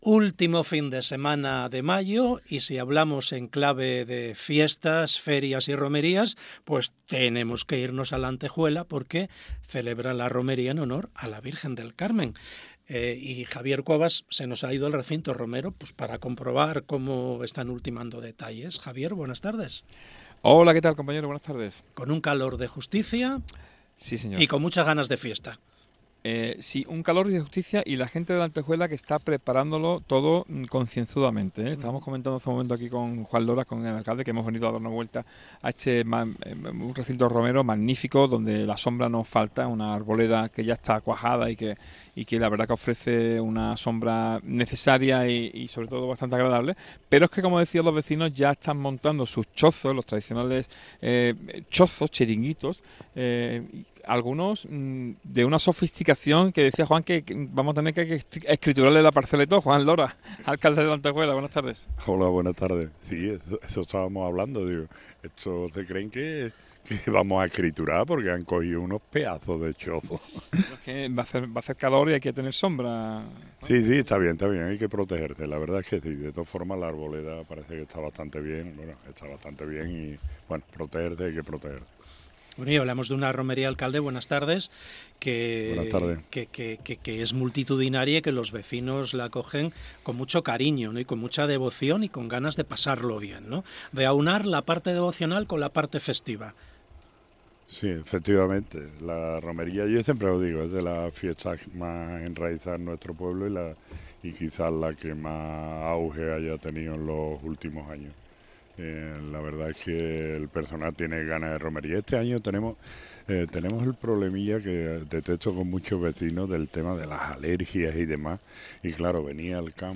Entrevista Juan Lora, alcalde de Lantejuela.